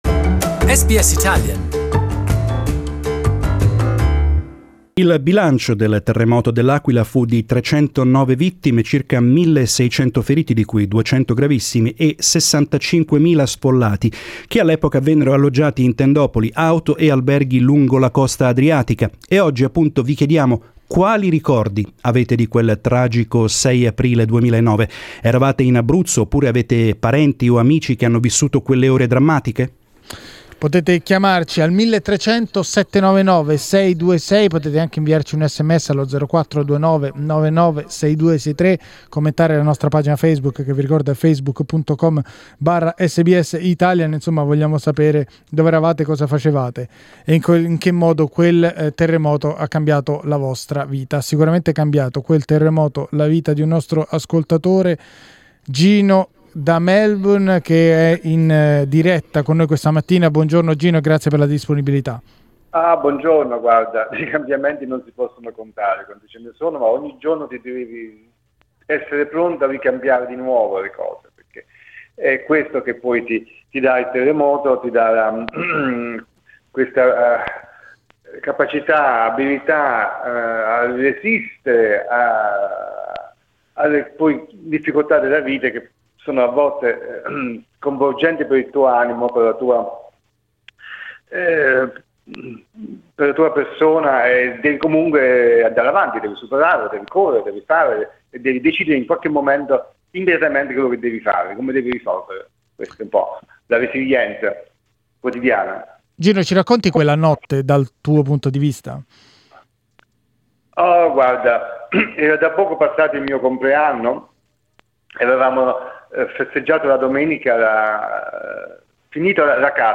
The 6.3-magnitude earthquake that struck the central region of Abruzzo on April 6, 2009 killed 309 people, left 70,000 homeless and destroyed much of the historic city of L'Aquila. Ten years on, we spoke with a survivor from the quake and a Red Cross volunteer who helped with the recovery efforts.